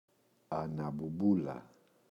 αναμπουμπούλα, η [anambu’mbula] – ΔΠΗ
αναμπουμπούλα-η.mp3